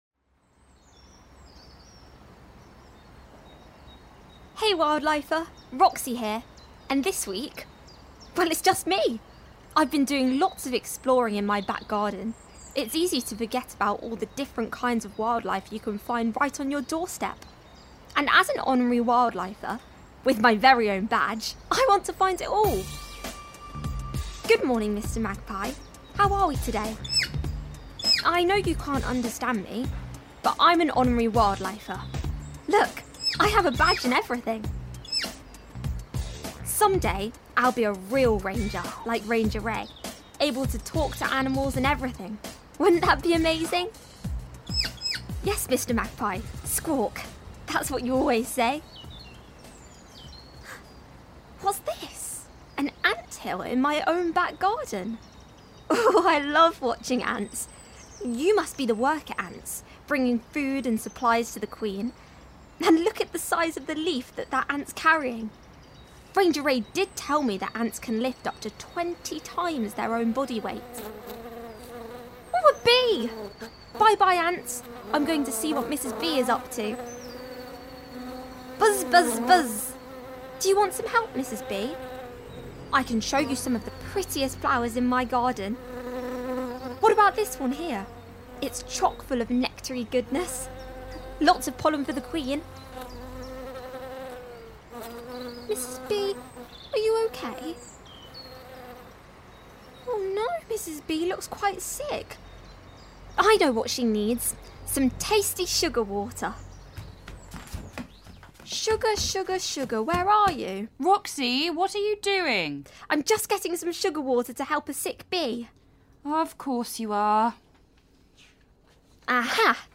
Other characters played by members of the ensemble.